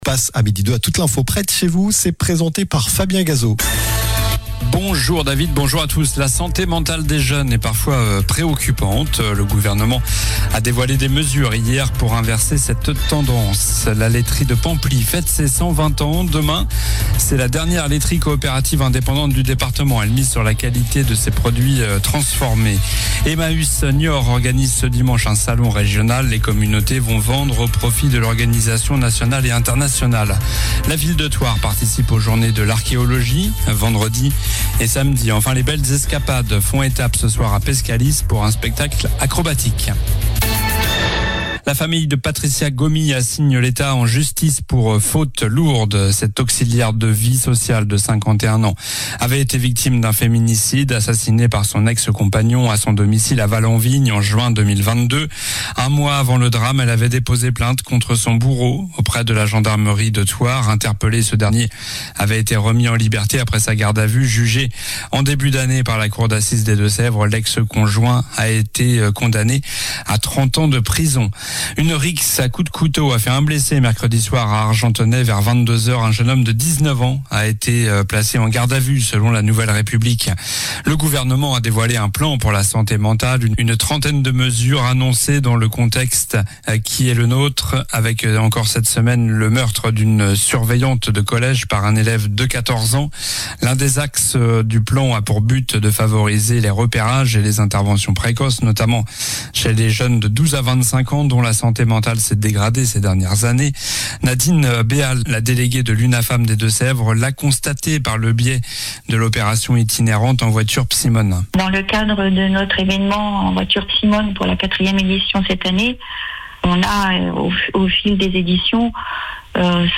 Journal du vendredi 13 juin (midi)